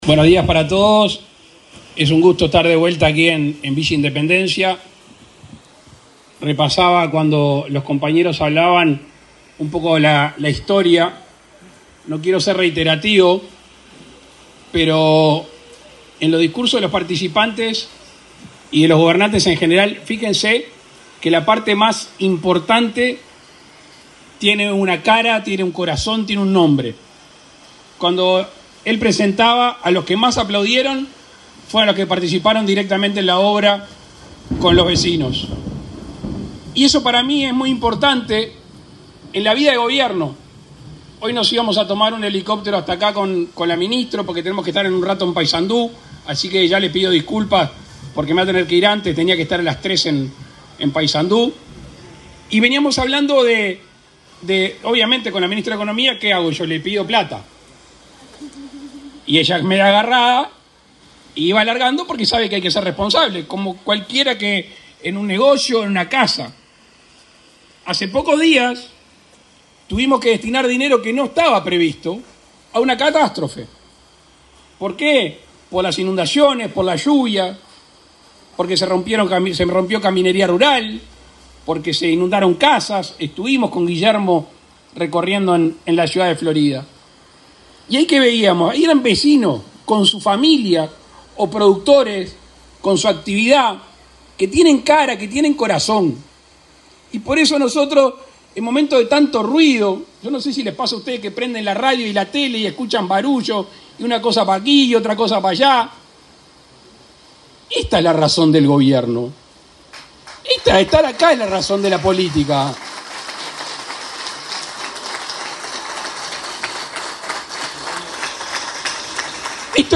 Palabras del presidente Luis Lacalle Pou
El presidente de la República, Luis Lacalle Pou, encabezó, este martes 7 en Independencia, Florida, la inauguración de un plan de viviendas de Mevir